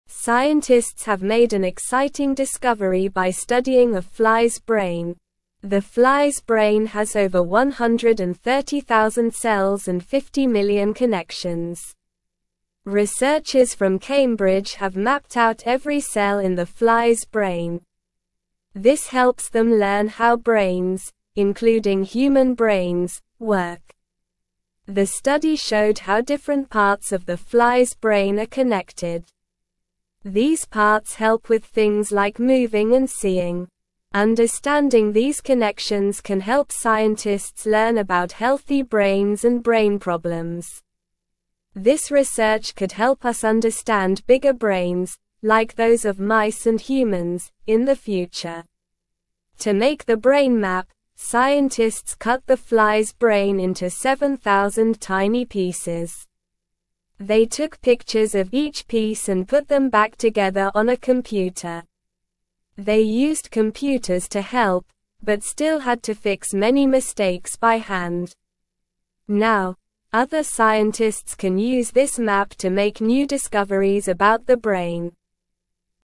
Slow
English-Newsroom-Lower-Intermediate-SLOW-Reading-Scientists-study-tiny-fly-brain-to-learn-more.mp3